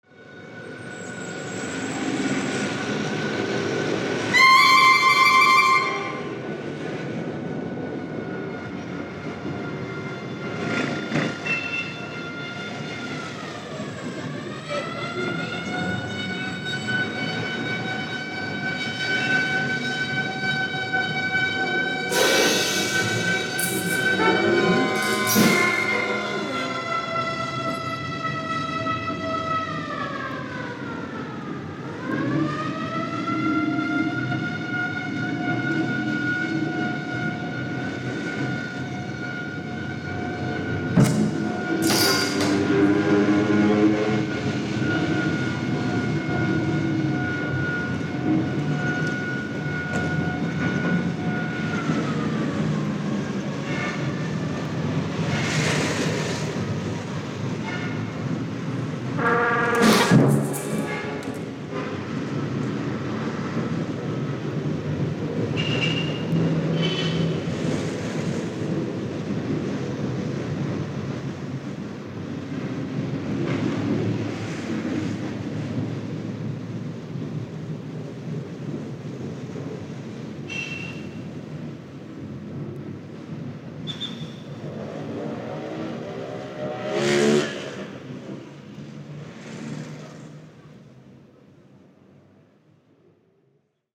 クラクションやら、身の回りの音も取り入れた面白いアルバムです。